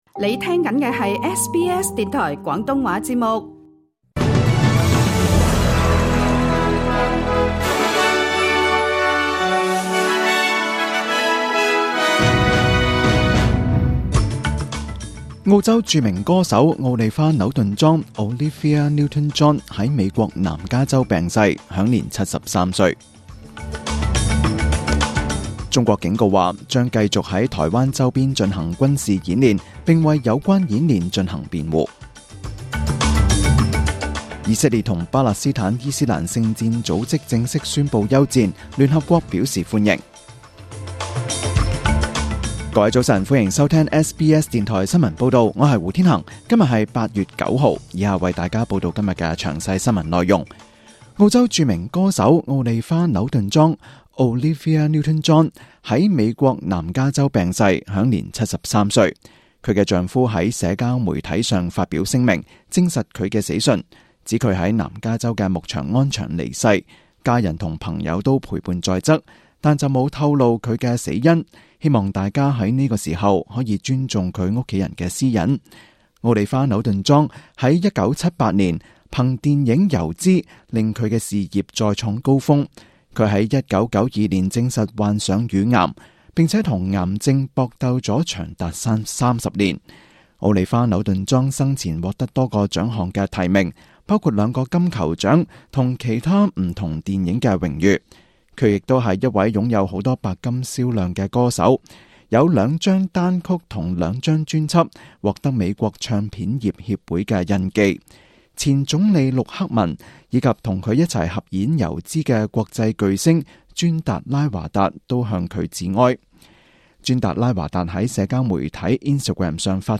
Cantonese Sunday News 2018-11-25 at 10:00 am.